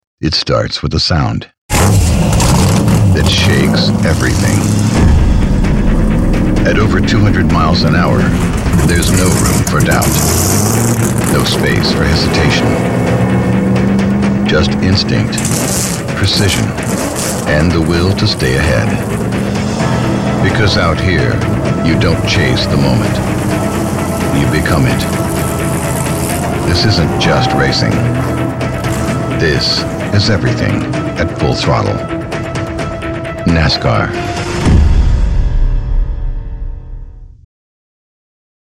Adult (30-50) | Older Sound (50+)
0401NASCAR_-_Production.mp3